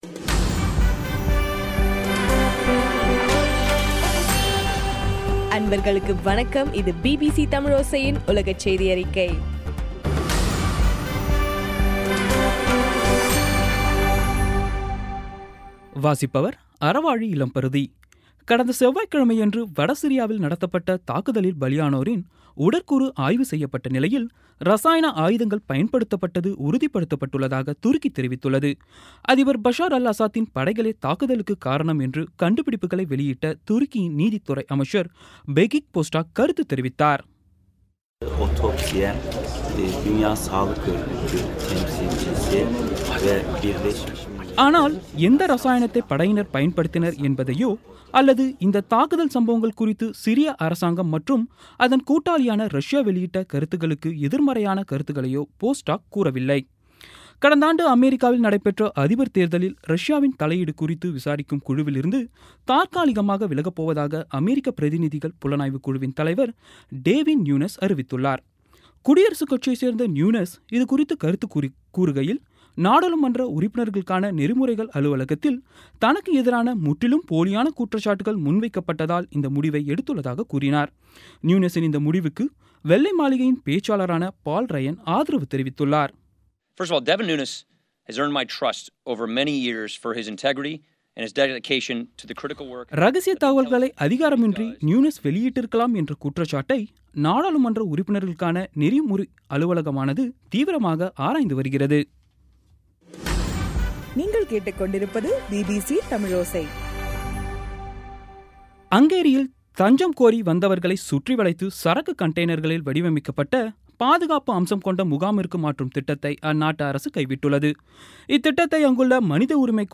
பிபிசி தமிழோசை செய்தியறிக்கை (06/04/2017)